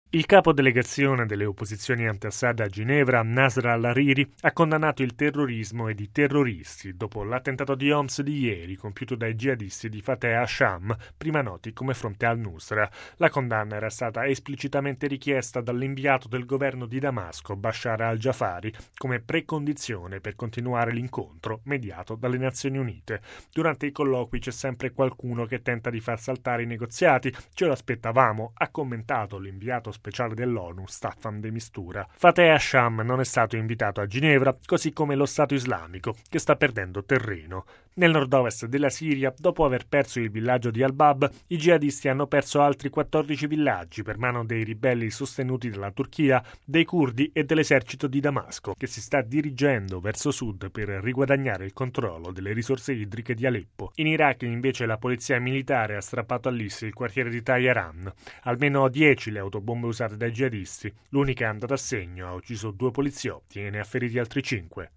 Bollettino radiogiornale del 26/02/2017